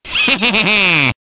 One of Waluigi's voice clips in Mario Kart DS